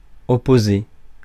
Ääntäminen
Tuntematon aksentti: IPA: /ɔ.po.ze/